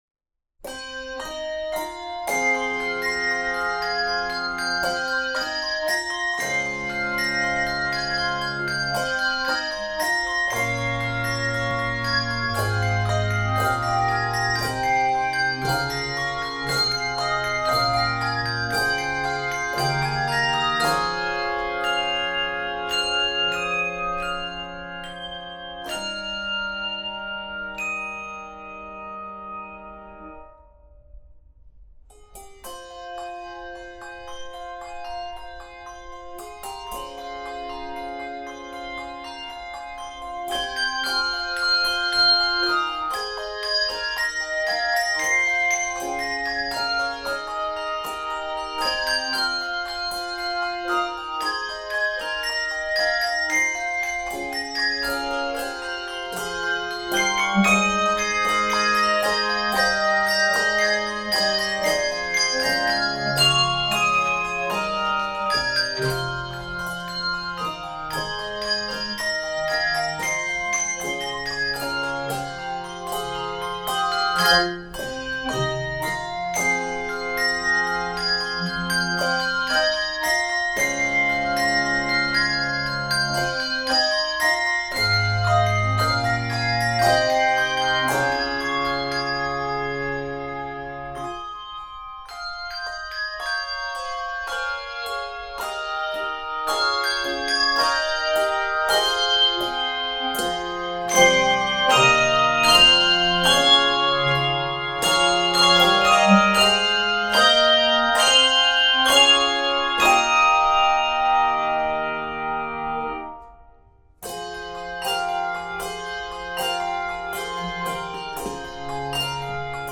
Voicing: 3-6 Octave Handbells and Handchimes